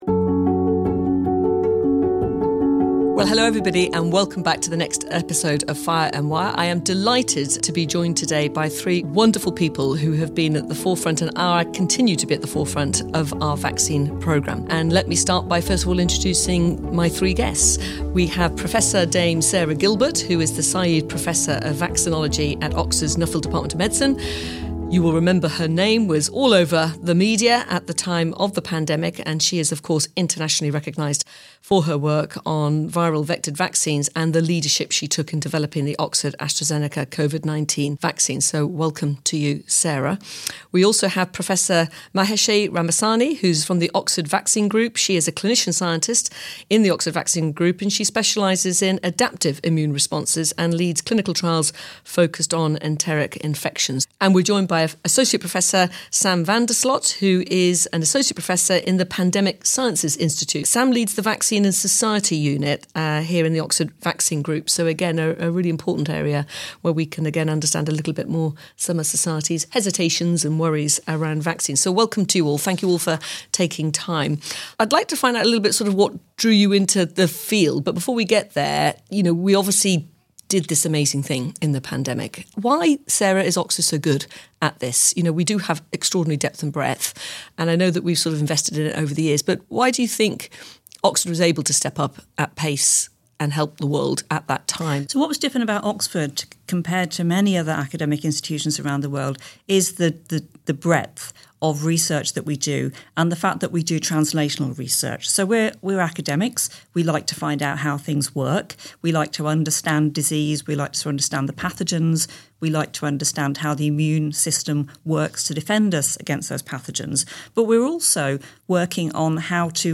View transcript Headliner Embed Embed code See more options Share Facebook X Subscribe Three Oxford vaccine experts join Vice-Chancellor Professor Irene Tracey in the latest Fire & Wire podcast. They discuss Oxford’s critical role in the response to COVID-19, and how trust and preparedness will shape future outbreak responses.